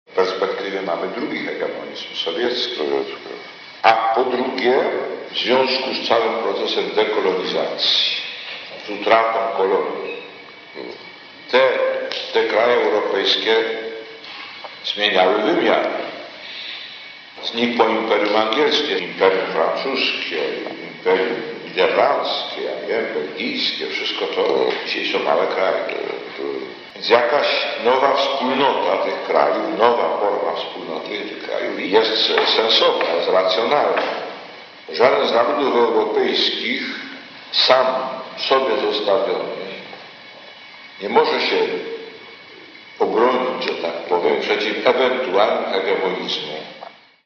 Taśmy z rozmowami nagranymi w czasie kolacji, w prywatnych apartamentach Ojca Świętego Jana Pawła II w roku 1988 i 1992, przekazał dziennikarz i polityk Jaś Gawroński.